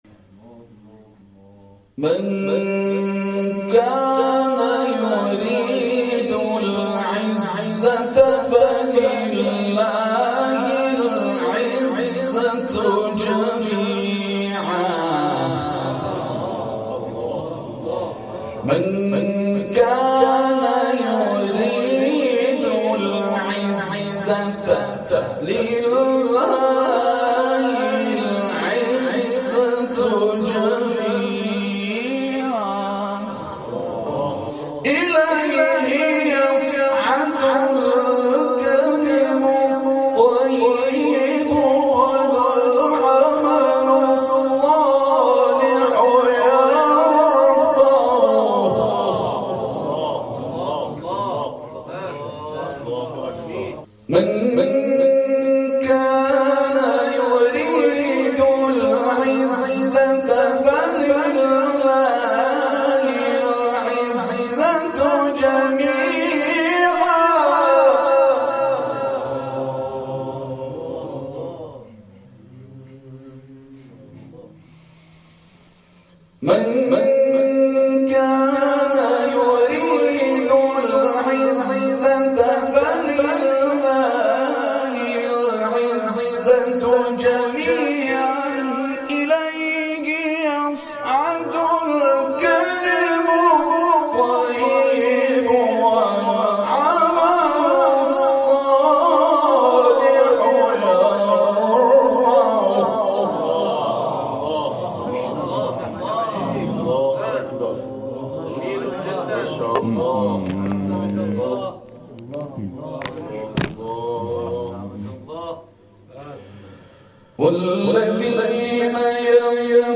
تلاوت قرآن ، سوره فاطر